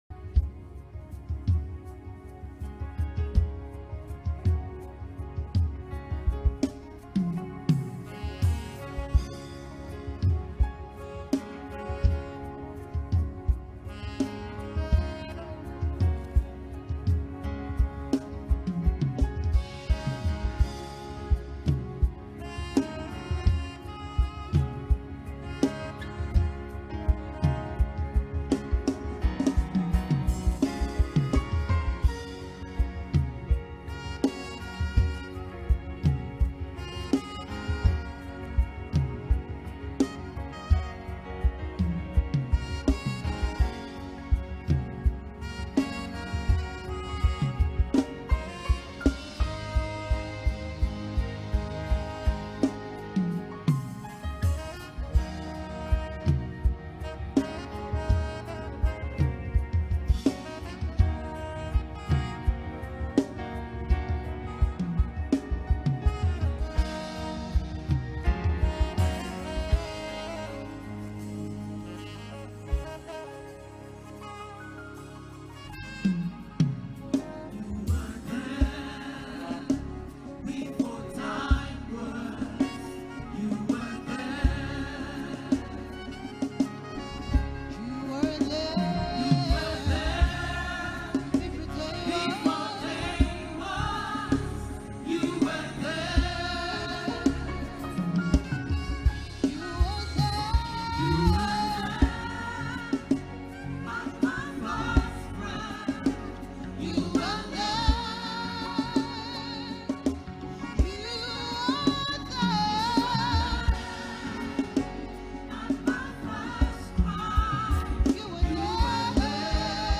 soul lifting deeper worship song